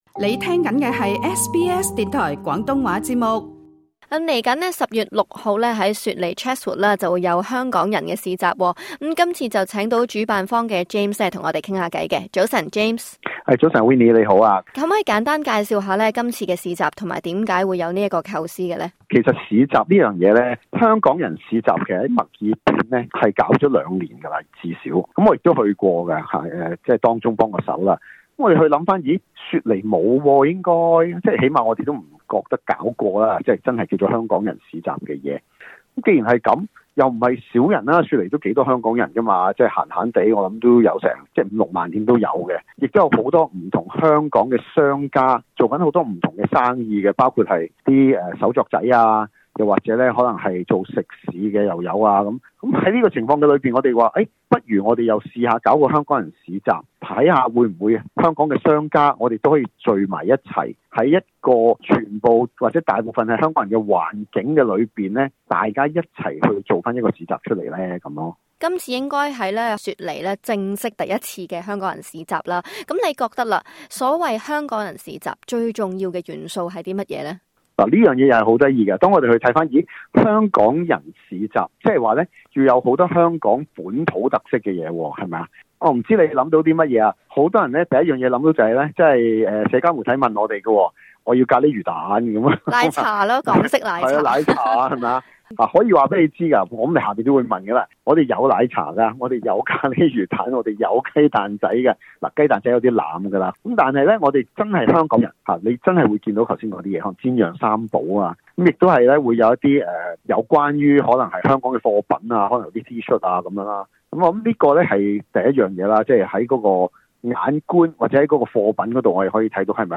【社區專訪】雪梨首個「香港人市集」 本周日車士活舉行